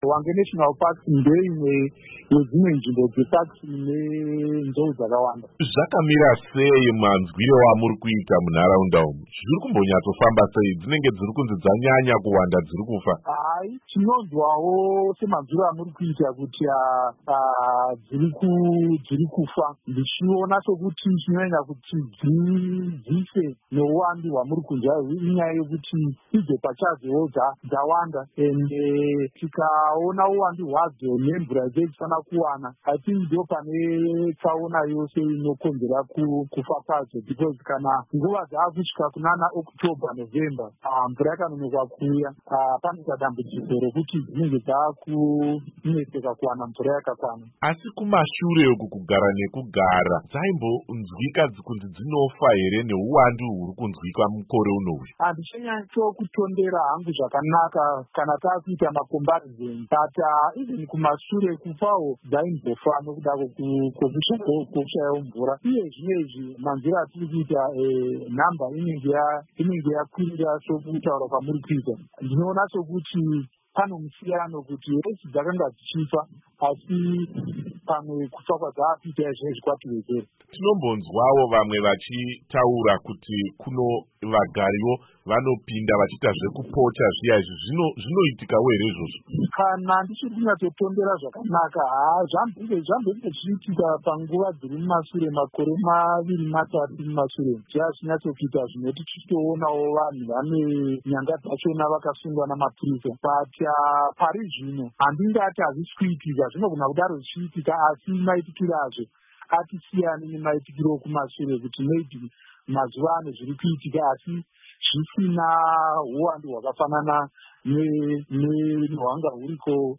Hurukuro na Va Stanley Torima